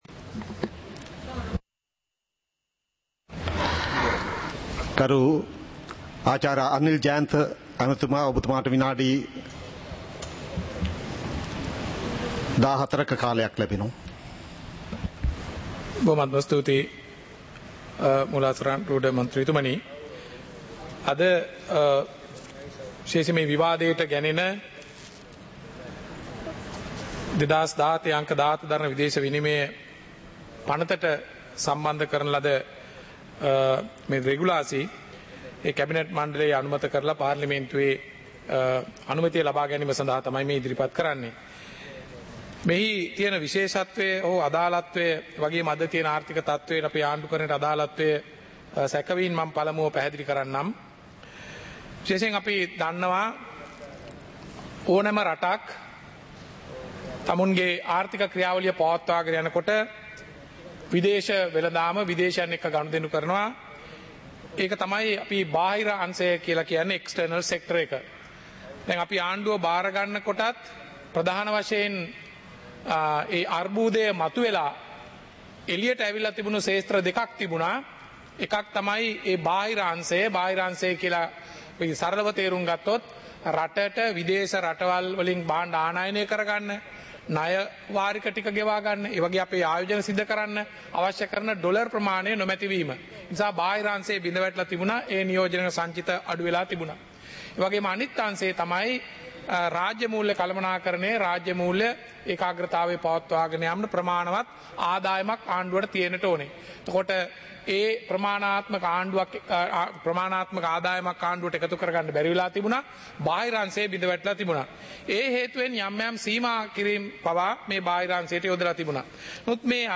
இலங்கை பாராளுமன்றம் - சபை நடவடிக்கைமுறை (2026-03-03)
நேரலை - பதிவுருத்தப்பட்ட